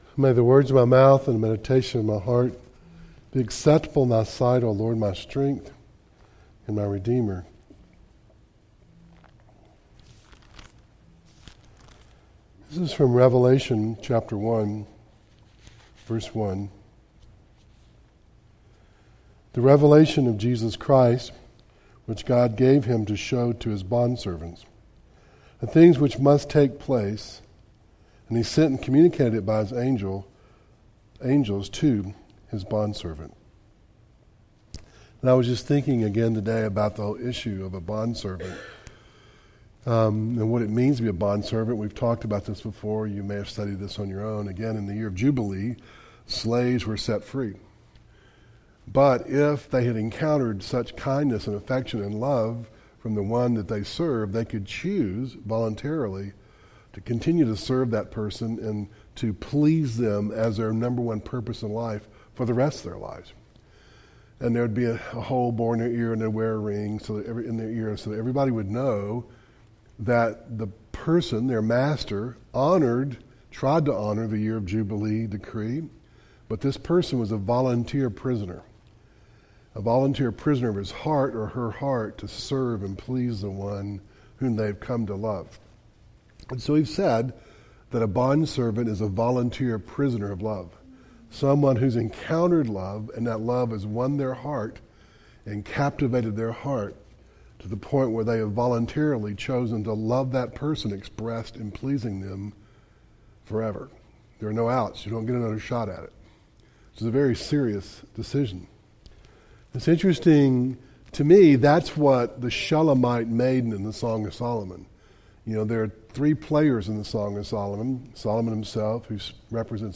Service Type: Devotional